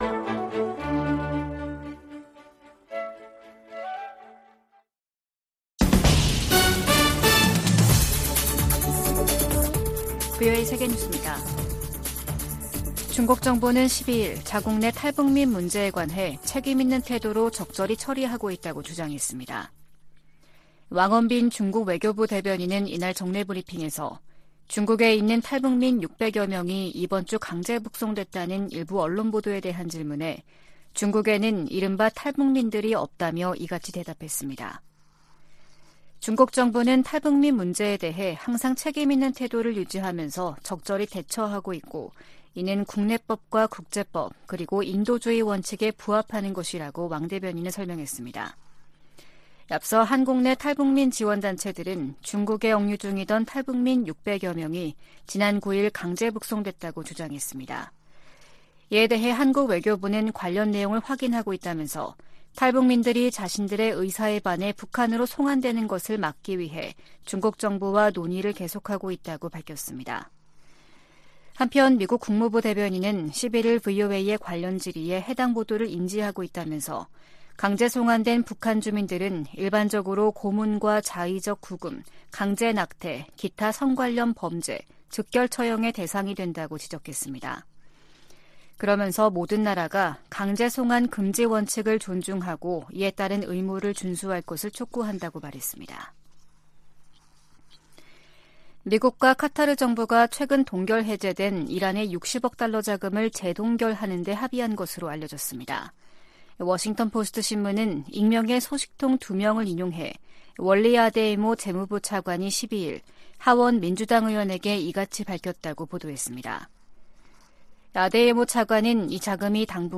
VOA 한국어 아침 뉴스 프로그램 '워싱턴 뉴스 광장' 2023년 10월 13일 방송입니다. 백악관이 이스라엘 지상군의 가자지구 투입에 대비해 민간인 대피 대책을 논의 중이라고 밝혔습니다. 한국을 방문한 미국 상원의원들이 윤석열 한국 대통령과 만나 대북 상호 방위에 대한 초당적 지지를 재확인했습니다. 9.19 남북군사합의가 팔레스타인 무장 정파 하마스식의 북한 기습 도발에 대한 감시·정찰 능력을 제한한다고 미국 전문가들이 지적했습니다.